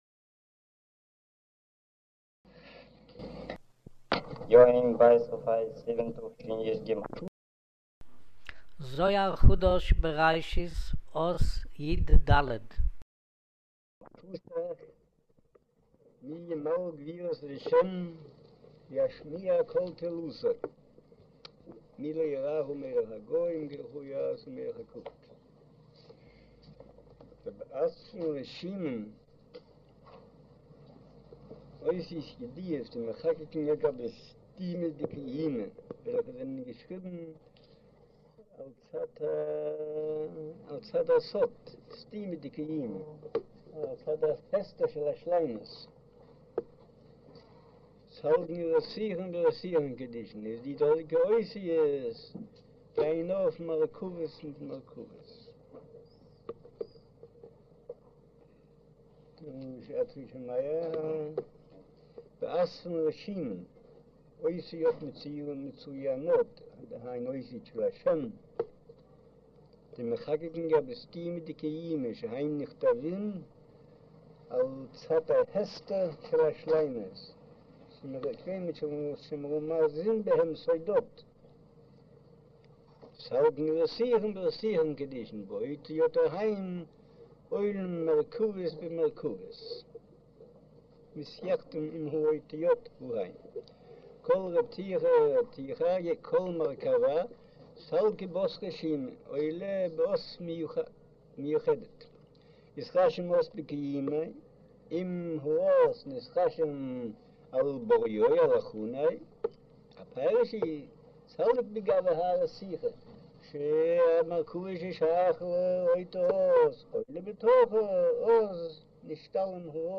אודיו - שיעור מבעל הסולם זהר חדש, בראשית, אות יד' - כג'